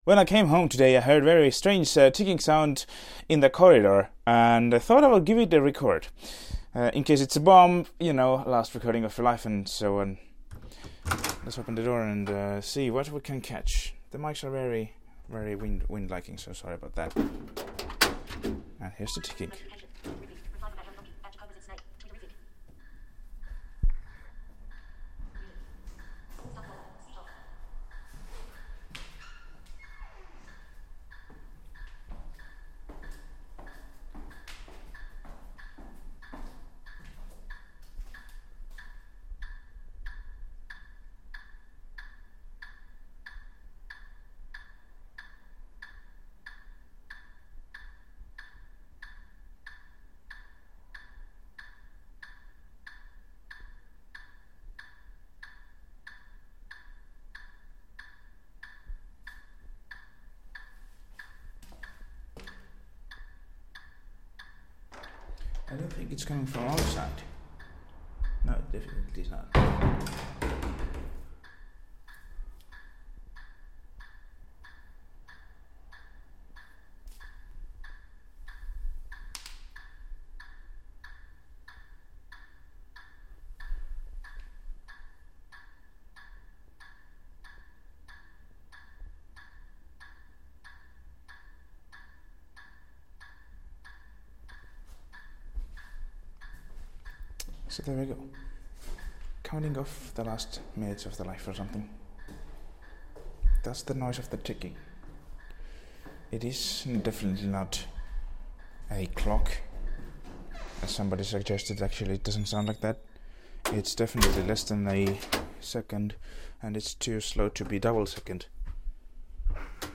Is it ticking away our last minutes? We will know, if its the last thing I will ever do. Ticking in the corridor!
Maybe the last minutes of the life in this house. Recorded with the olympus ls_100 internals.